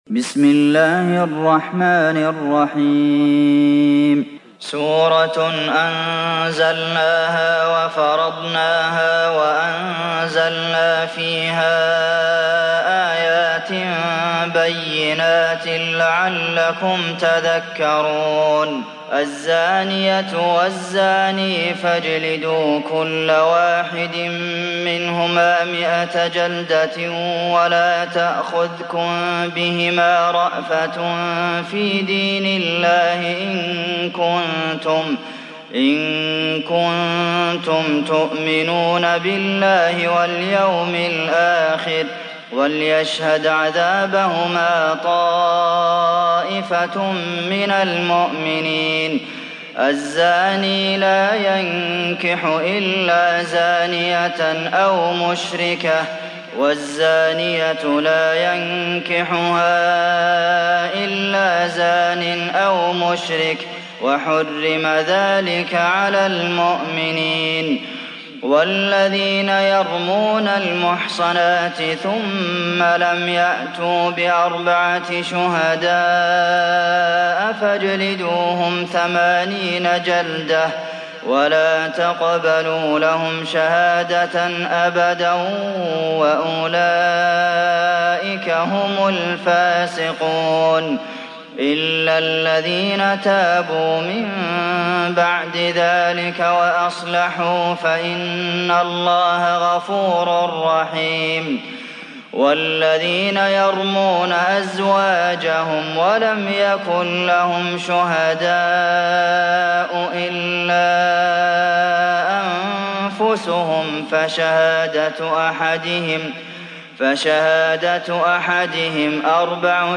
Sourate An Nur mp3 Télécharger Abdulmohsen Al Qasim (Riwayat Hafs)